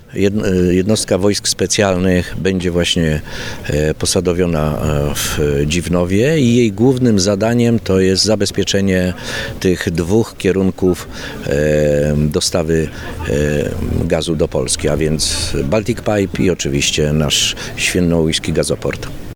Aby poprawić bezpieczeństwo infrastruktury przesyłowej w województwie zachodniopomorskim, zostanie odtworzona jednostka wojsk specjalnych. Na antenie Twojego Radia mówił o tym Michał Jach, Przewodniczący Sejmowej Komisji Obrony Narodowej.